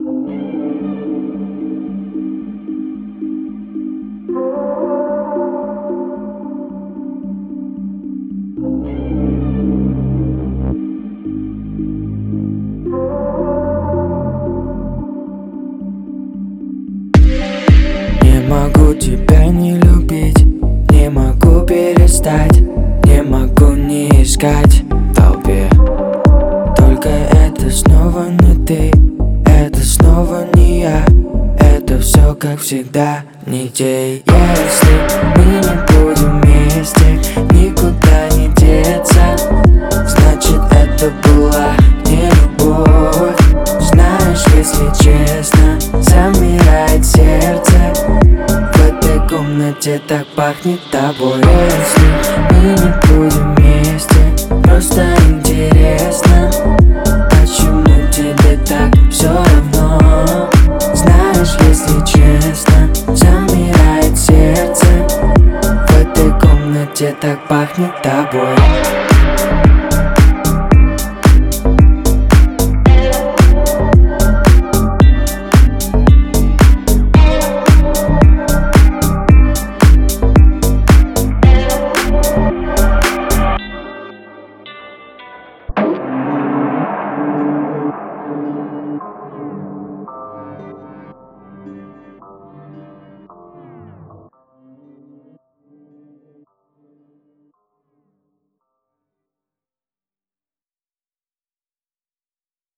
это яркая и мелодичная композиция в жанре поп